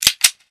GUN ARMS 1-L.wav